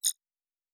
pgs/Assets/Audio/Sci-Fi Sounds/Interface/Error 02.wav at master
Error 02.wav